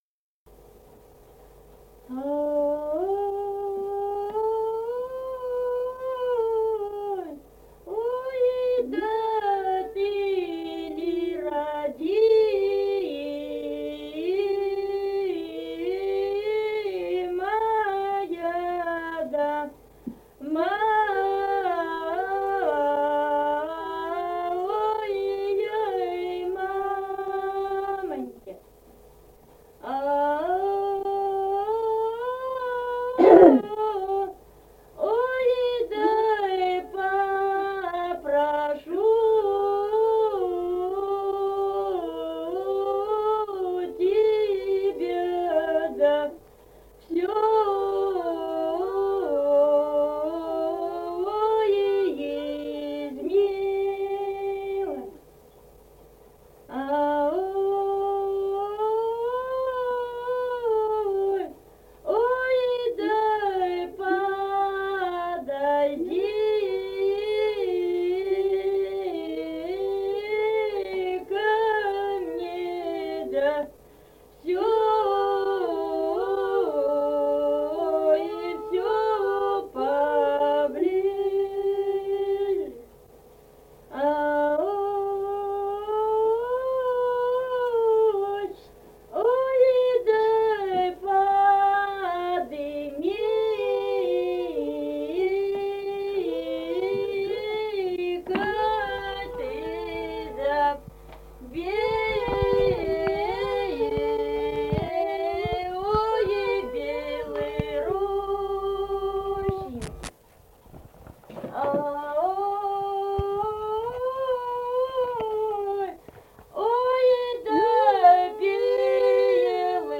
Республика Казахстан, Восточно-Казахстанская обл., Катон-Карагайский р-н, с. Белое, июль 1978.